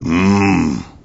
gutterball-3/Gutterball 3/Commentators/Master/zen_ummmmm.wav at fceb2d37df47dc12bc660c23fa1f6ad4d1ce774d
zen_ummmmm.wav